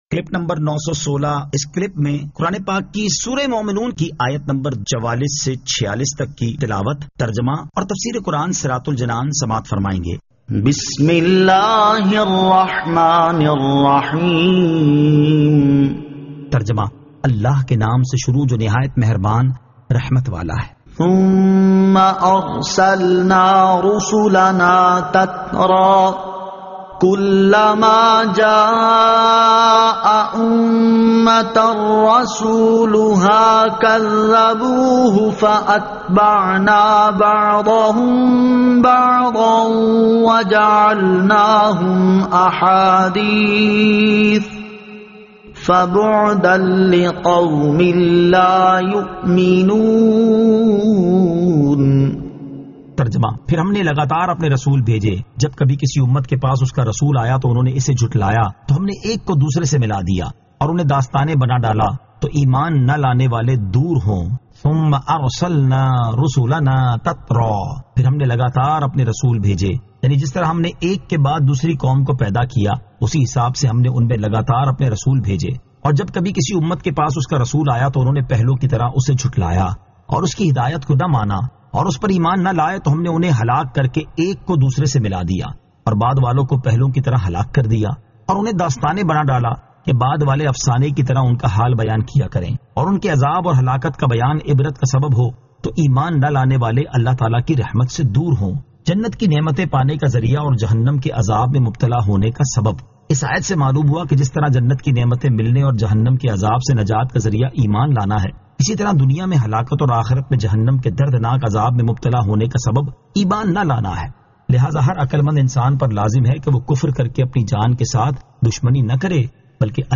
Surah Al-Mu'minun 44 To 46 Tilawat , Tarjama , Tafseer